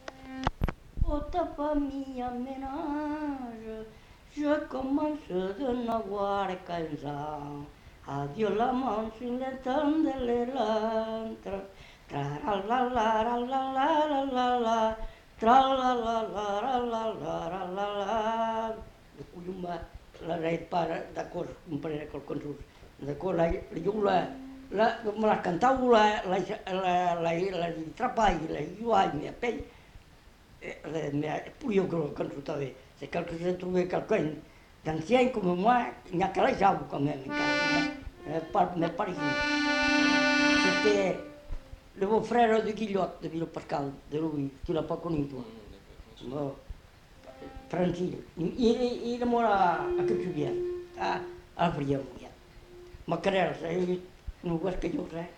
Genre : chant
Effectif : 1
Type de voix : voix d'homme
Production du son : chanté ; fredonné